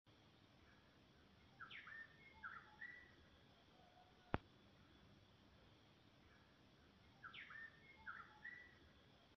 Black-bellied Wren (Pheugopedius fasciatoventris)
Life Stage: Adult
Location or protected area: Ibague, Zona de Boquerón
Condition: Wild
Certainty: Recorded vocal